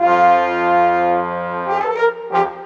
Rock-Pop 07 Brass 03.wav